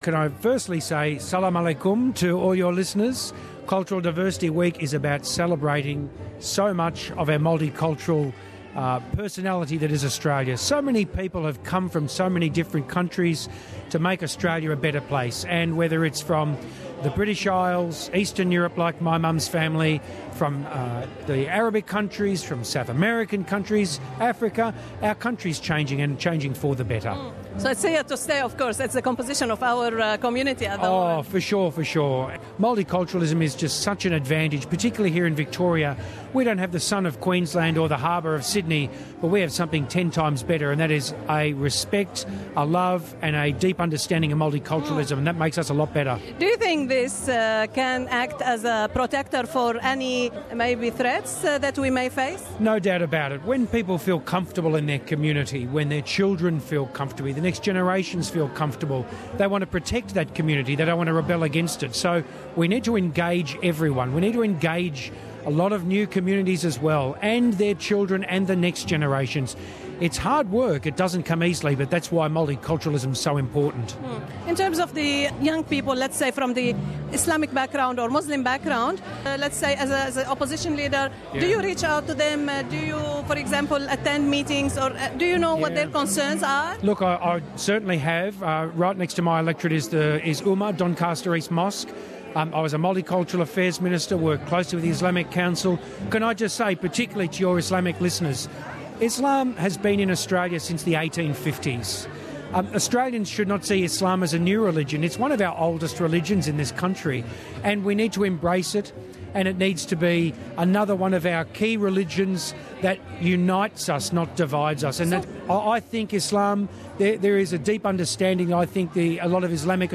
خلال الحفل السنوي الذي تقيمه حكومة الولاية في فيكتوريا احتفالا بأسبوع التعددية الثقافية